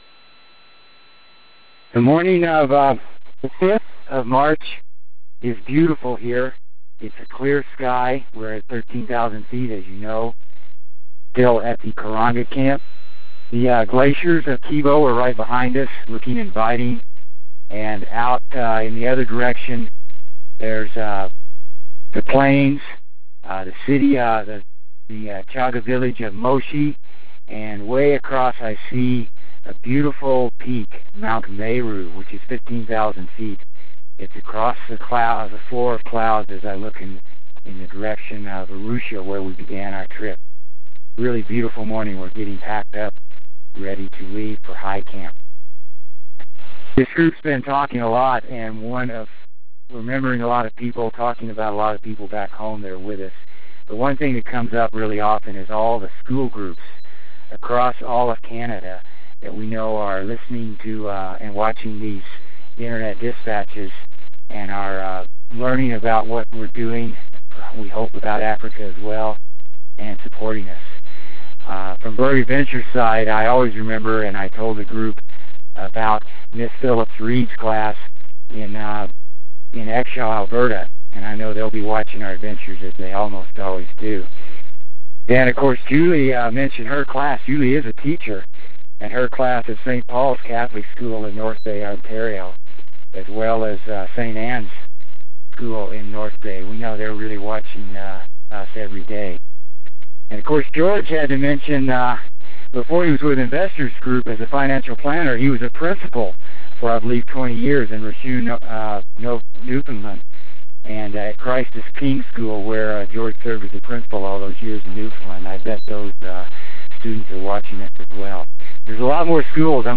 March 5 – A Stunning Morning at Karenga Camp